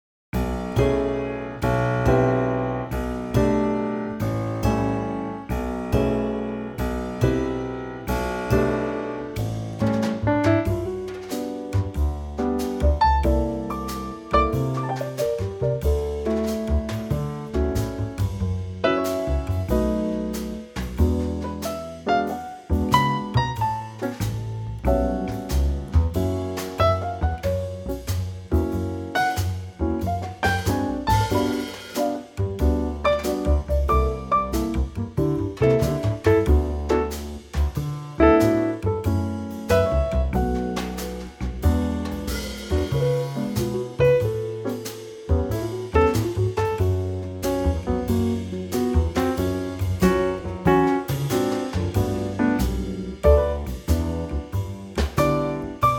Unique Backing Tracks
key - D - vocal range - Bb to D